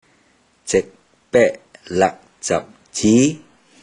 Click each Romanised Teochew word to listen to how the Teochew word is pronounced.
zeig10ped01lag10zab10ji2